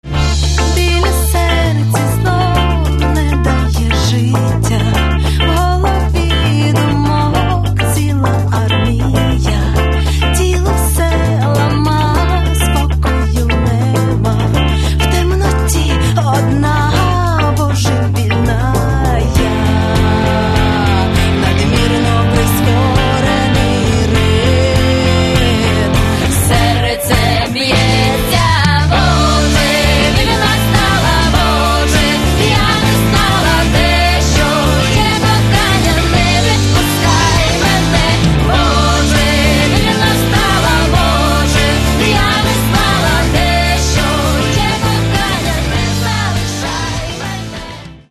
Каталог -> Поп (Легкая) -> Этно-поп
этно-поп-рок